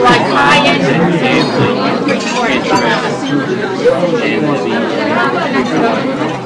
The Party Sound Effect
the-party.mp3